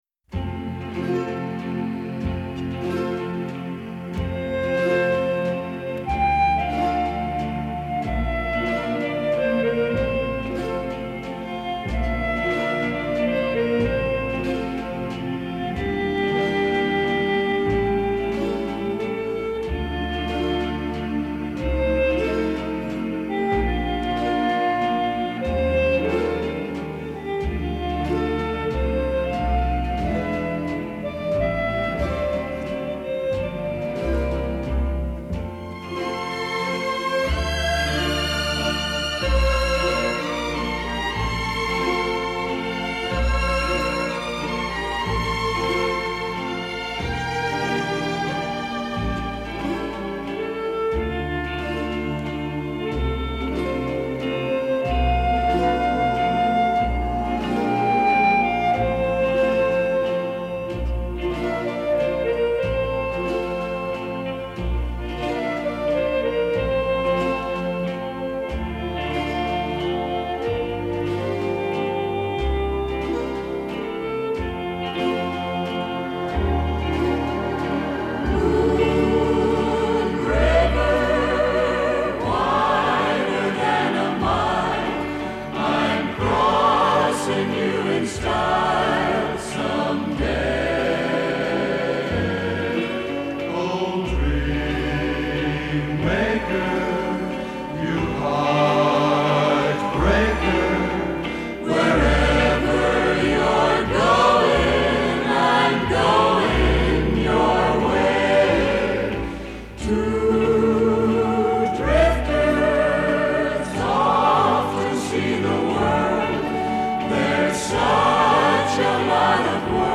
音乐风格：Instrumental Pop / Lounge / Easy Listening / Jazz
音乐品质：Lossless | 790 kbps | 44100 Hz | Stereo | 2 Channel